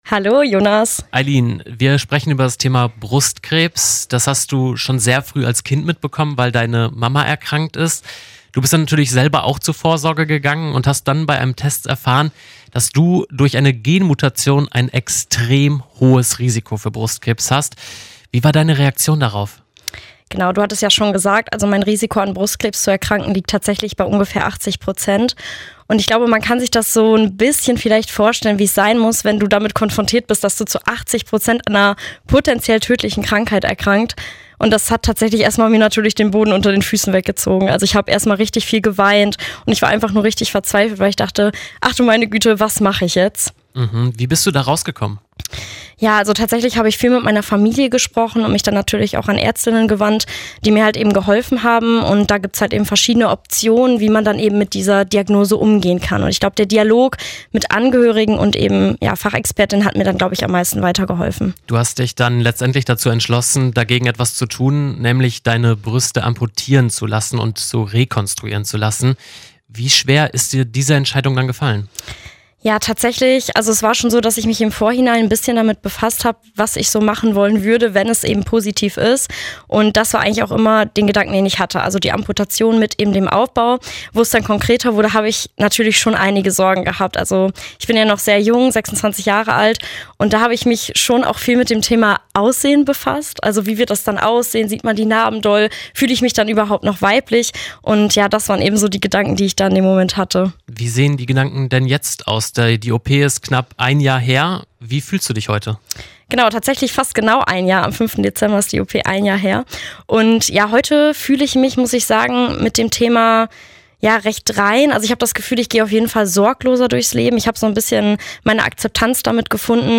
Im RADIO RST-Interview erzählt sie ihre Story.